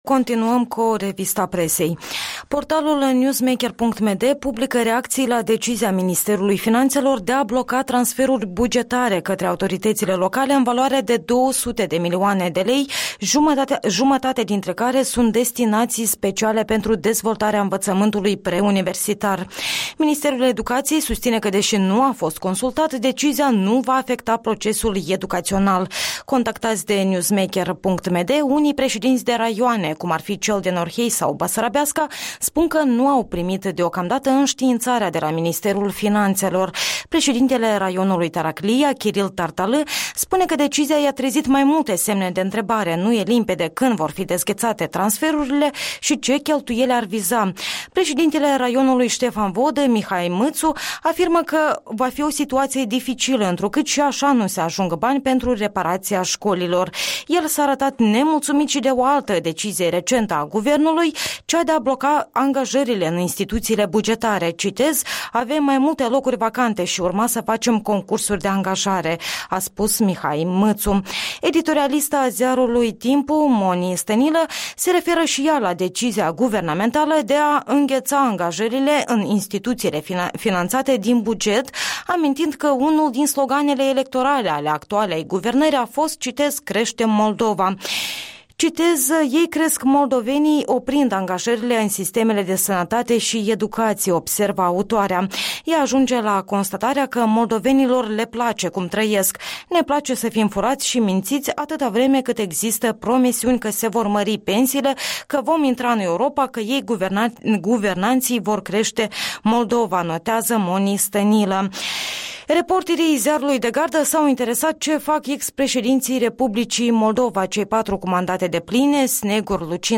Revista presei matinale